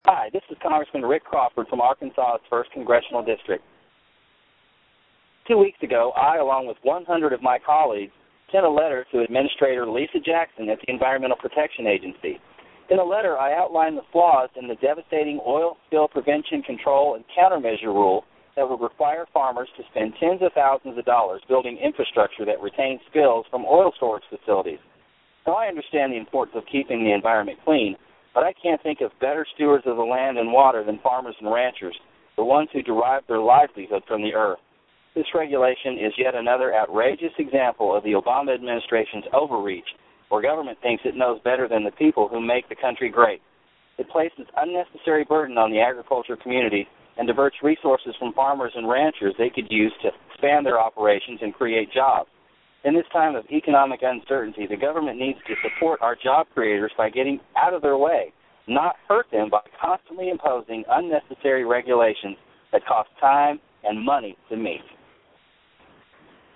The Ag Minute, guest host Rep. Rick Crawford discusses the latest efforts by the Environmental Protection Agency (EPA) to impose unnecessary regulations on farmers and ranchers. The agency is attempting to implement a rule under the oil Spill Prevention, Control, and Countermeasure (SPCC) program that would require agricultural producers to make structural improvements to any oil storage facilities with a capacity of over 1,320 gallons.
The Ag Minute is Chairman Lucas's weekly radio address that is released from the House Agriculture Committee.